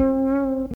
Bass (18).wav